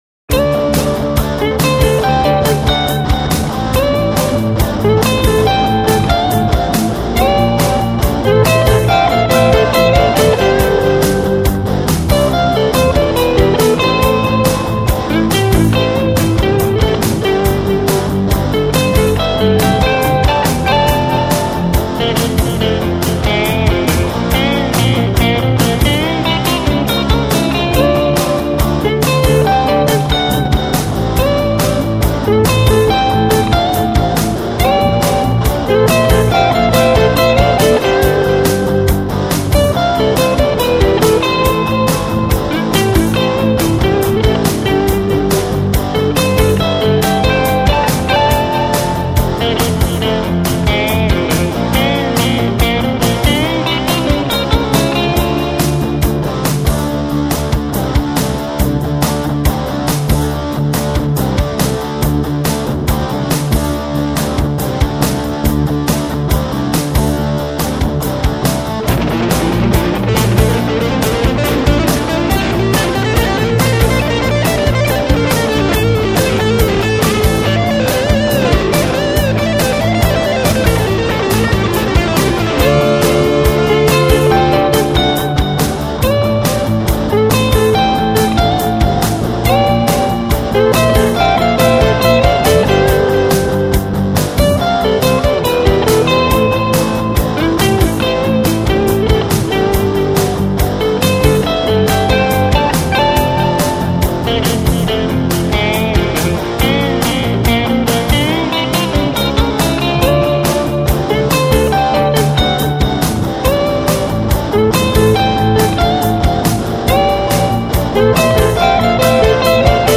I also use various software for "virtual" instruments, recording, mixing and editing, as well as further digital FX.
I also use sound samples on certain tracks.
all guitars, vocals, samples and drum/instrument programming